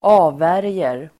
Uttal: [²'a:vär:jer]